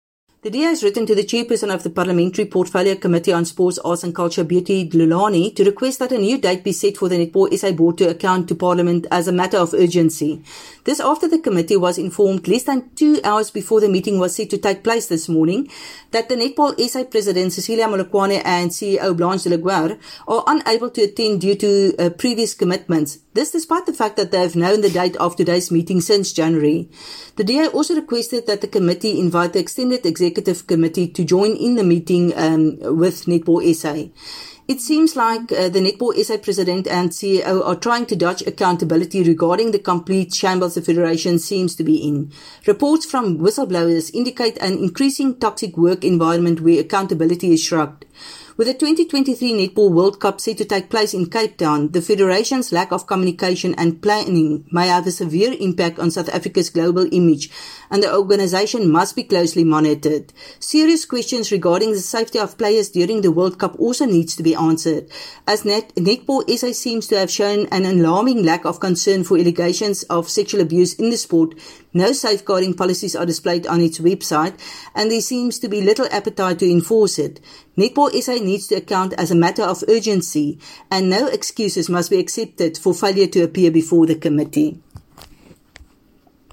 Afrikaans soundbites by Veronica van Dyk MP.